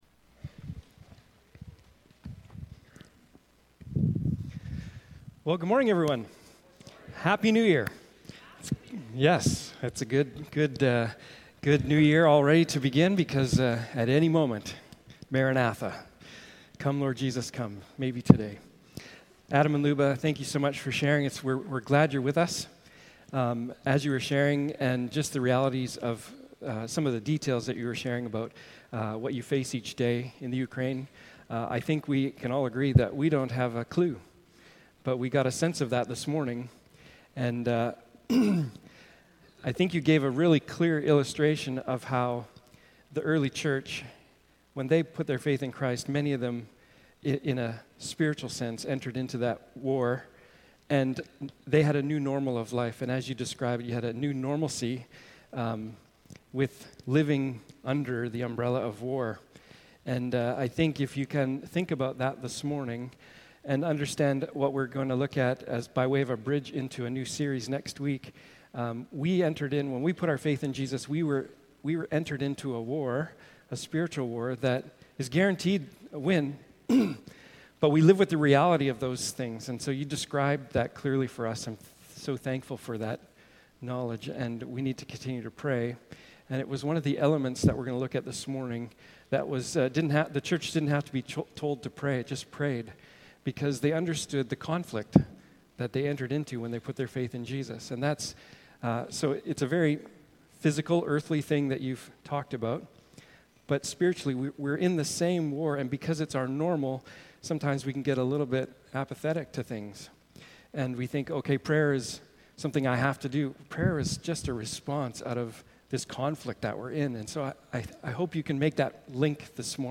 Passage: Acts 2:37-47 Service Type: Morning Service